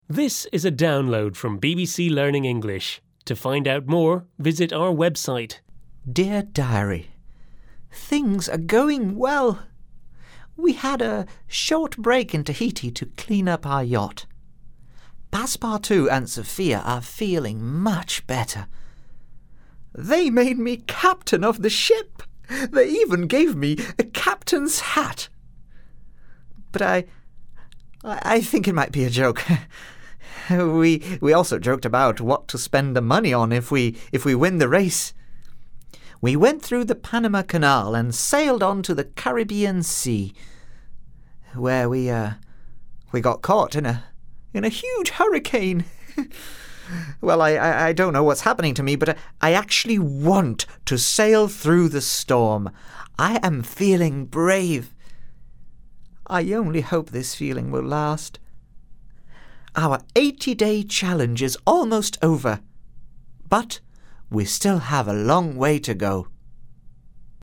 unit-9-4-1-u9_eltdrama_therace_audio_diary_download.mp3